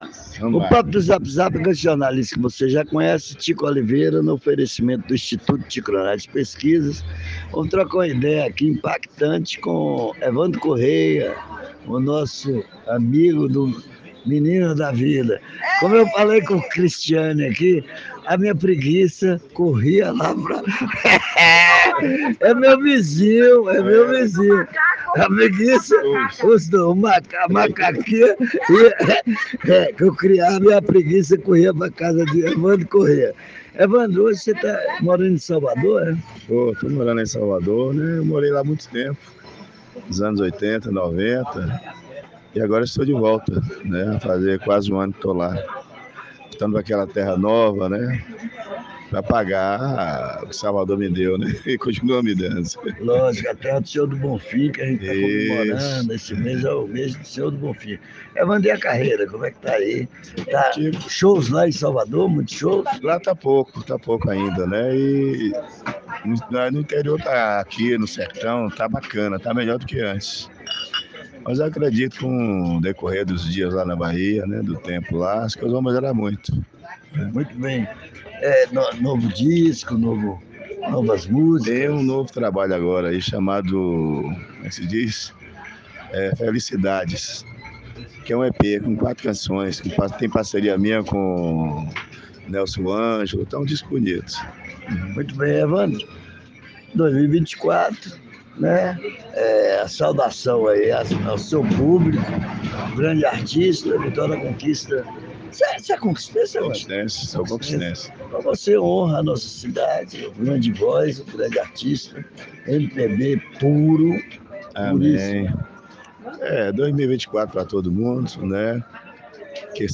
no papo do zap zap zap. O artista revelou que voltou a morar na capital do estado Salvador -BA, mas está na terra natal e pretende lançar mais um trabalho em março.